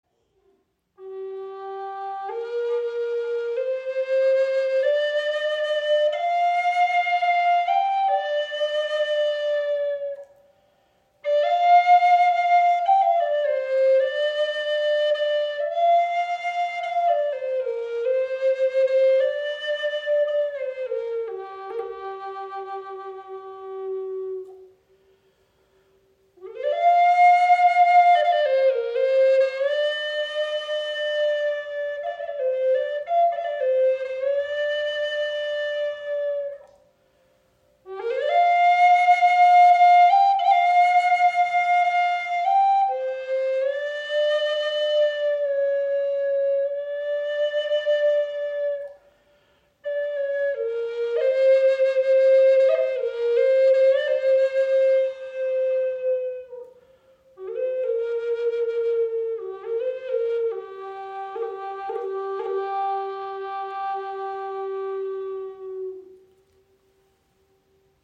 Die Red Tail Hawk Flöte in G Moll verbindet warmen, herzlichen Klang mit spielerischer Leichtigkeit
• Icon Sanfter, warmer Klang mit tieferer Stimme als die Sparrow Hawk Flöte
Ihre Klangfarbe ist etwas tiefer und voller als die unserer Sparrow Hawk Flöte, was sie zu einem sehr gefühlvollen Begleiter für verschiedenste Musikstile macht.